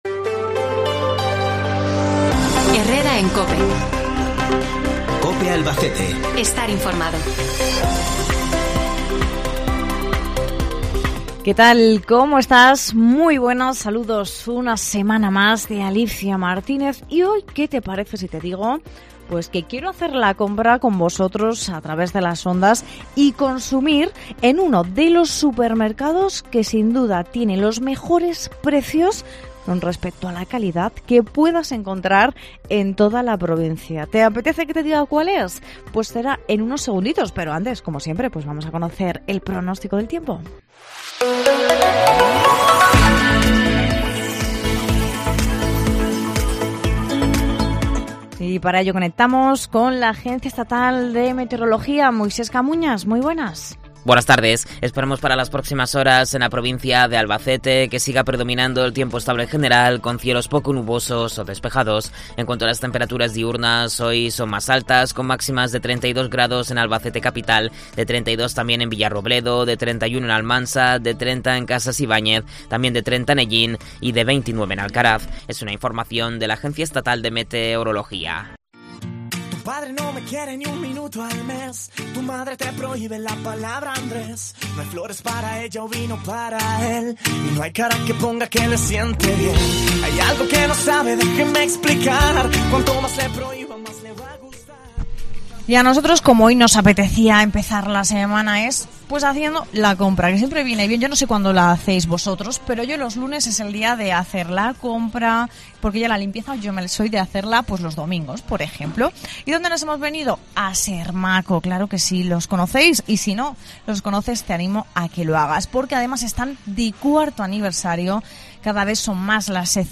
El equipo de Cope Albacete ha trasladado su stand de radio hasta este supermercado para conocer de primera mano el día a día de este supermercado.